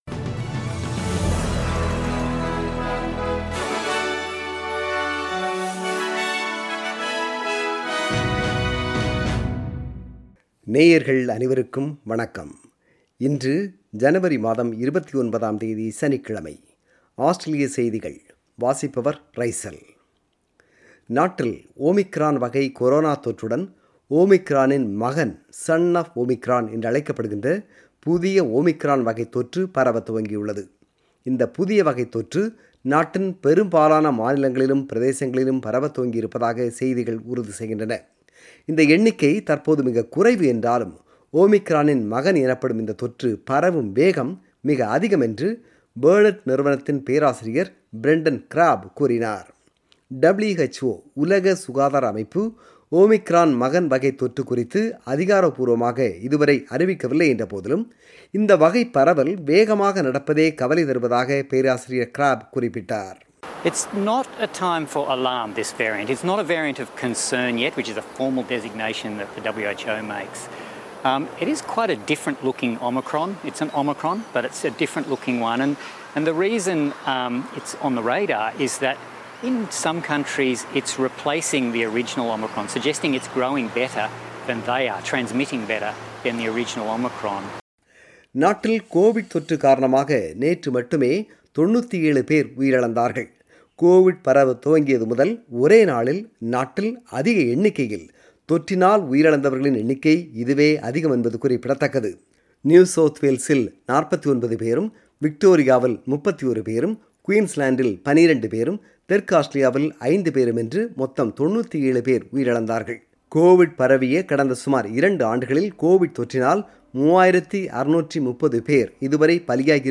Australian News: 29 January 2022 – Saturday